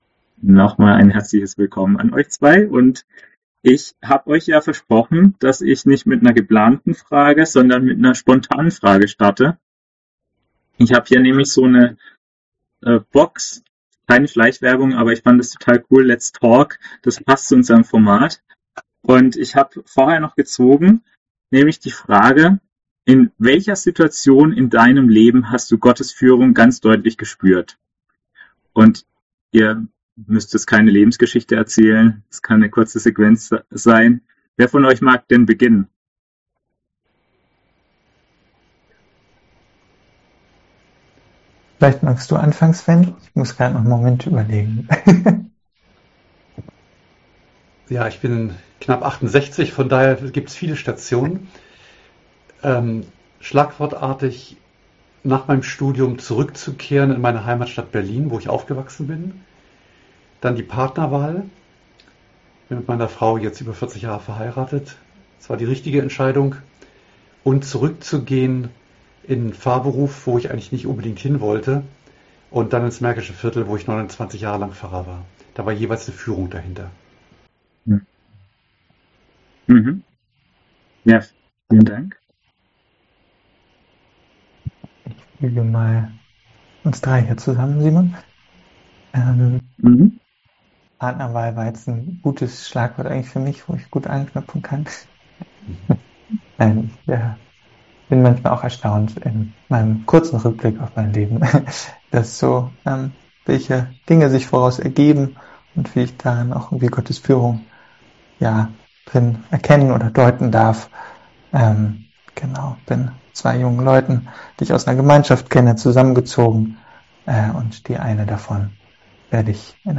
Let’s Talk! Gesprächsforum – zwei Theologen im Gespräch über Krankheit und Gesundheit
Digitales Gesprächsforum